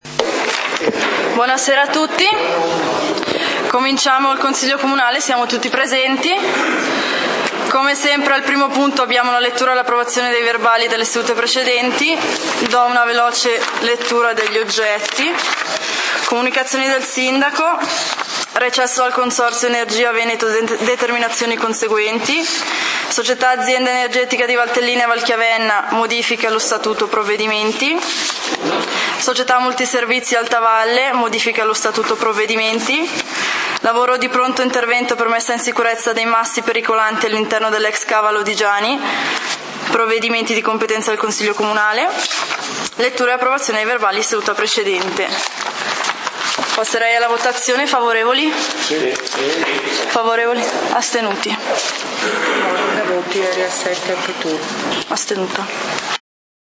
Punti del consiglio comunale di Valdidentro del 01 Agosto 2013